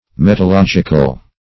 Meaning of metalogical. metalogical synonyms, pronunciation, spelling and more from Free Dictionary.
Metalogical \Met`a*log"ic*al\, a. Beyond the scope or province of logic.